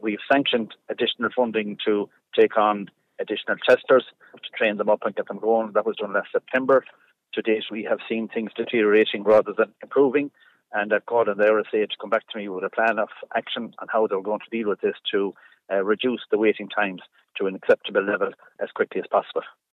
Minister Sean Canney says the current wait times are unacceptable: